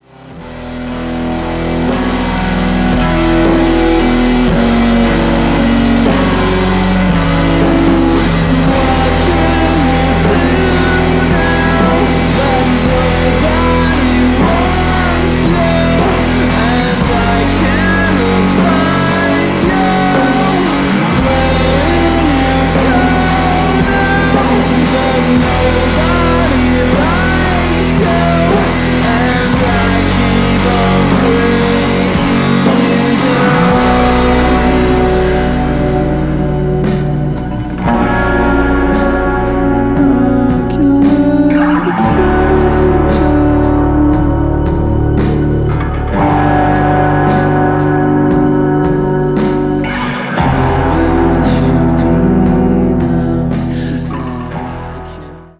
• Grunge
• Punk
• Rock
Trio